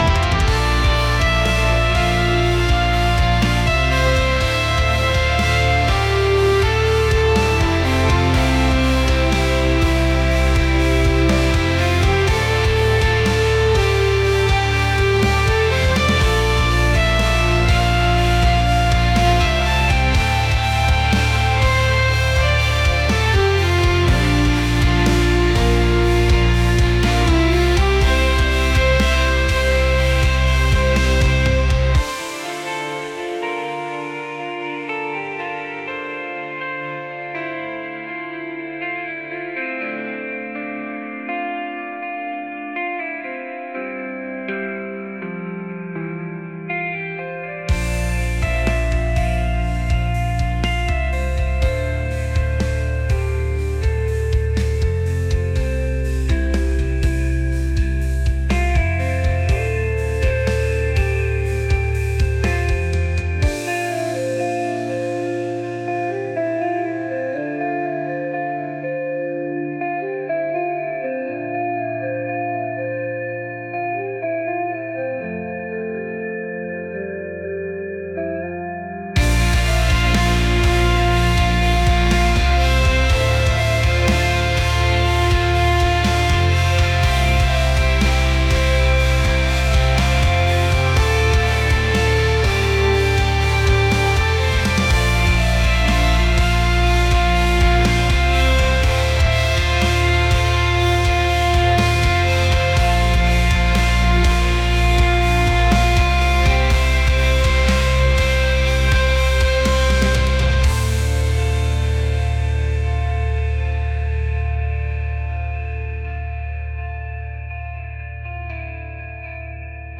rock | atmospheric